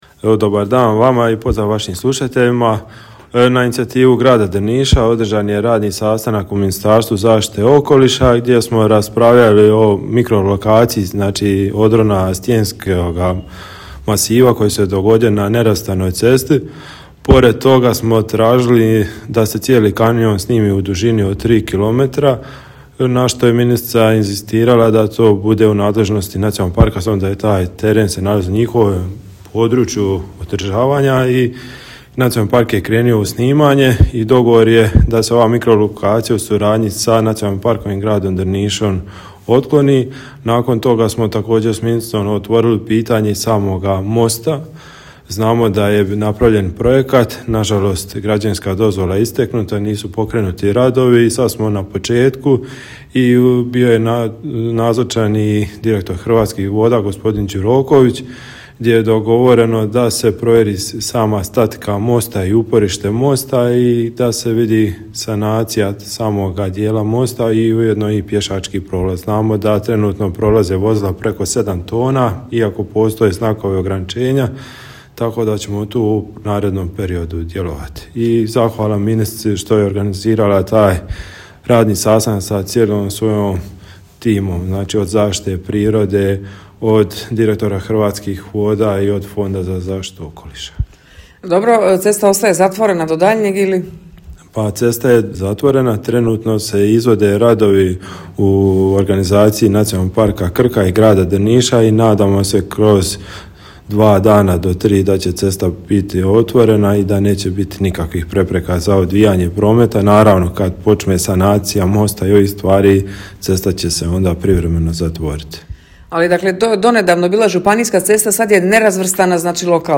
Za Radio Drniš gradonačelnik Dželalija je kazao: